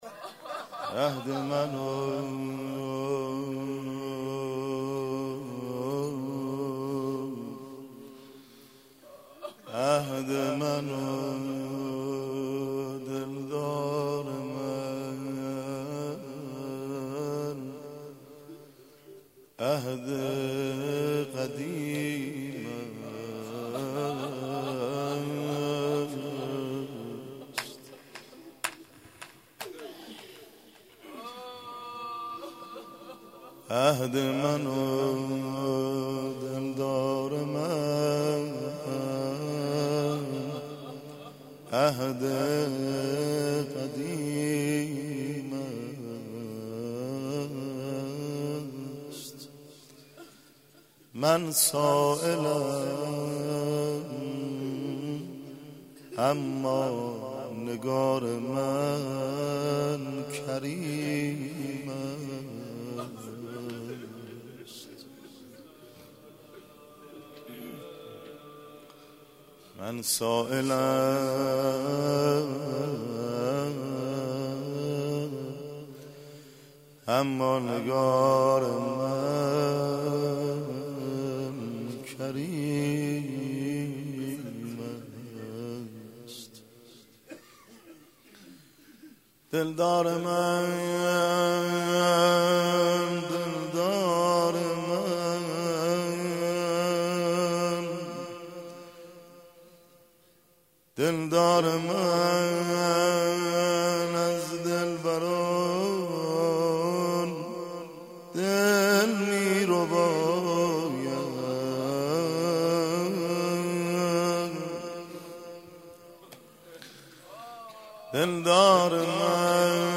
شب سوم رمضان 95